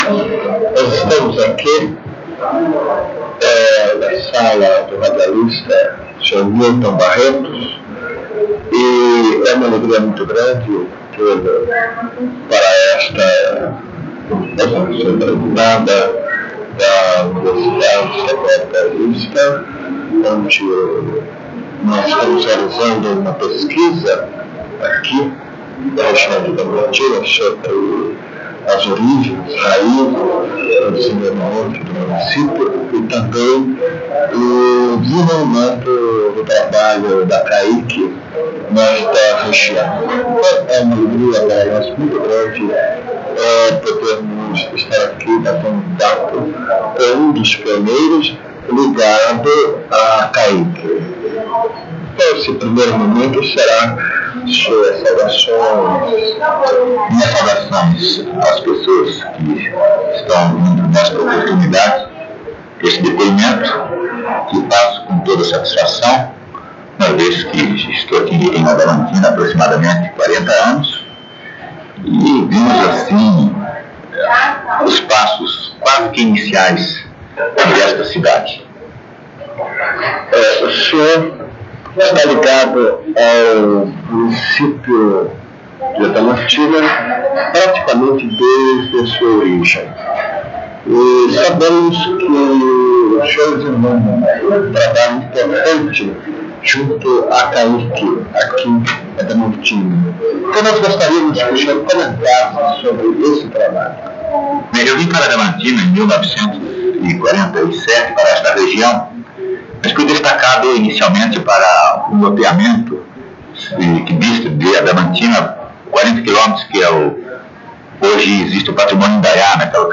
Entrevista
*Recomendado ouvir utilizando fones de ouvido.